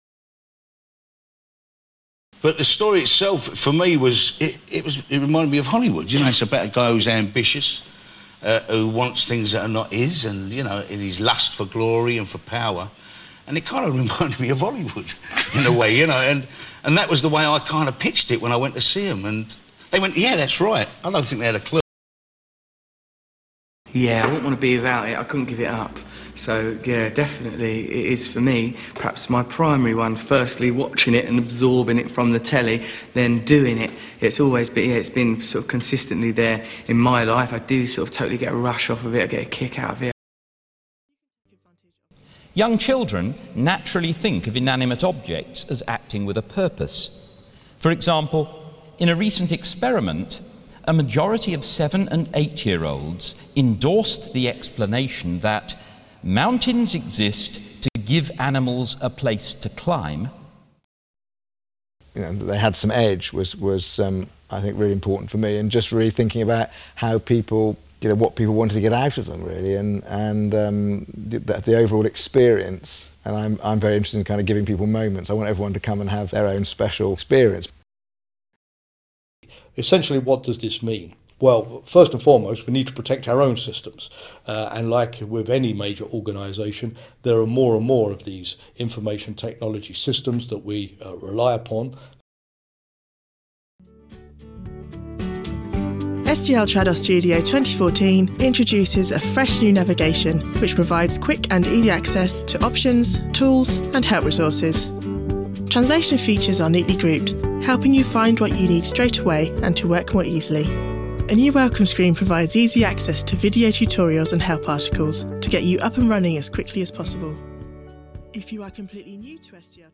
Example 1: A sequence of six different London or home counties SBE speakers:
Nevertheless, their accents sound very similar, a measure of the shared London features of the popular sociolects of London and the home counties.
– The next three exemplify an educated (Gimson) or standard (Wells) home counties sociolect of SBE, which is also my own accent.